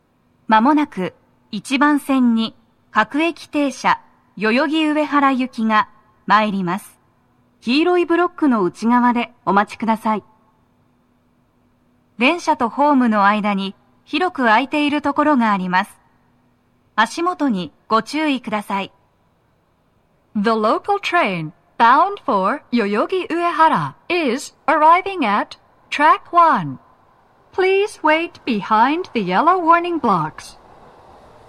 1番線の鳴動は、かなり遅めで、停車駅案内はありません。
女声
接近放送1